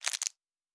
Plastic Foley 15.wav